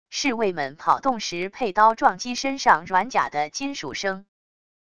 侍卫们跑动时佩刀撞击身上软甲的金属声wav音频